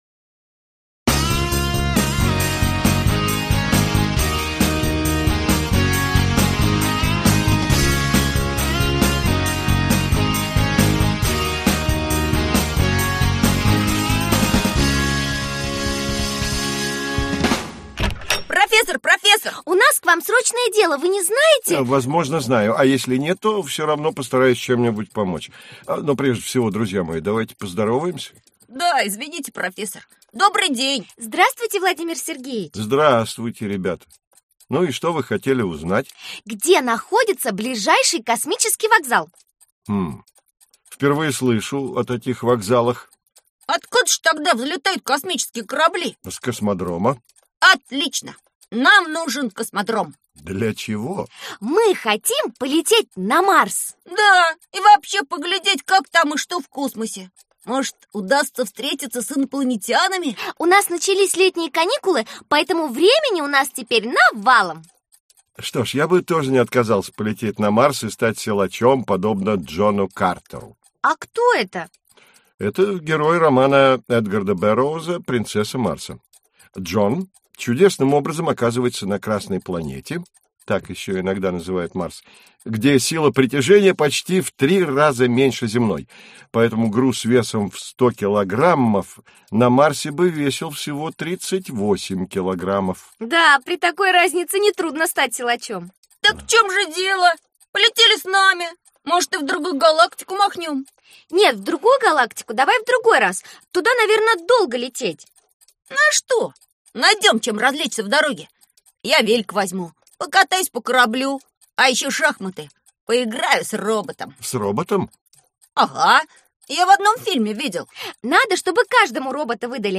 Аудиокнига Космонавтика | Библиотека аудиокниг